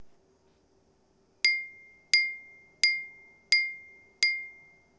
マイクロフォンは、SONYのワンポイントステレオ録音用、ECM-MS907を使いました。
4 これは、台所にあった、ガラスのコップを、ボールペンで、軽く打ったもの。　澄んだきれいな音がしました。　チン、チン、チン、、、、見たいな音です。
glass-cup1.wav